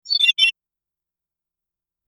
Sci Fi User Interface 03
Sci-fi_user_interface_03.mp3